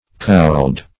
(kould)